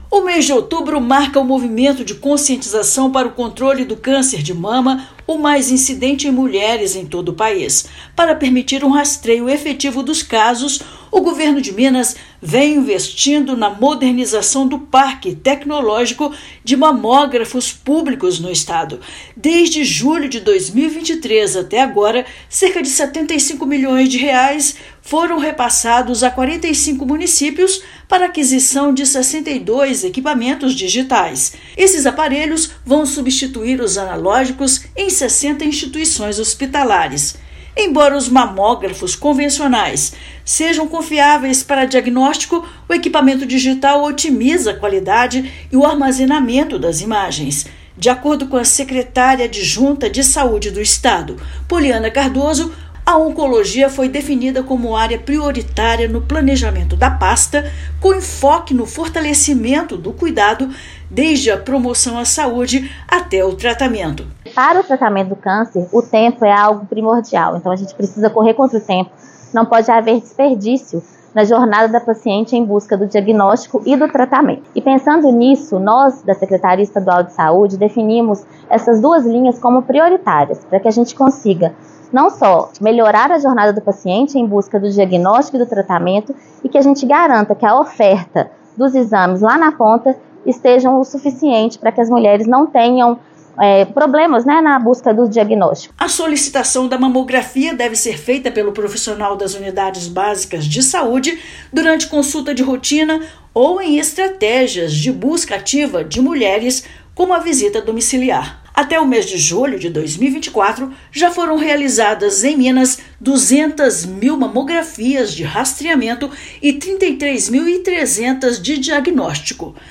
[RÁDIO] Governo de Minas investe na modernização de equipamentos para otimizar diagnóstico de câncer de mama
No último ano, R$ 75 milhões foram aplicados na aquisição de 62 mamógrafos digitais para 45 municípios mineiros. Ouça matéria de rádio.